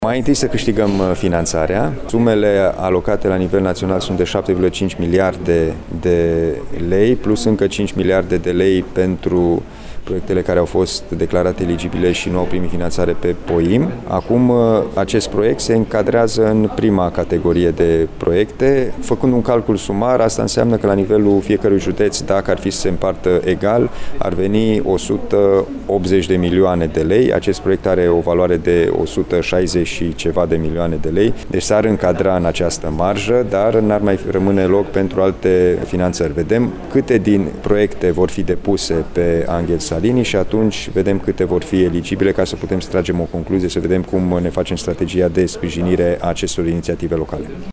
O astfel de decizie ar majora riscul de a bloca finanţarea altor proiecte, este de părere preşedintele CJ Timiş. Alin Nica spune că hotărârea va fi luată după ce se va ști câte dintre proiecte vor fi declarate eligibile.